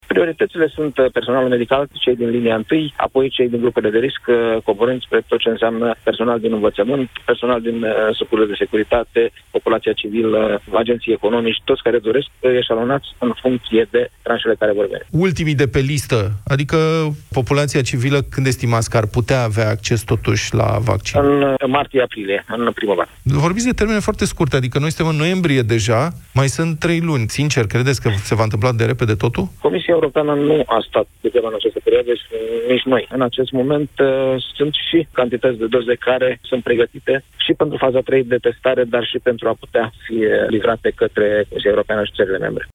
Ministrul Sănătăţii, Nelu Tătaru, a spus în această dimineaţă în emisiunea Deşteptarea că o primă tranşă din vaccinul anti-COVID ar putea veni în ţară la începutul anului viitor.
04nov-09-Nelu-Tataru-Desteptarea-prioritati.mp3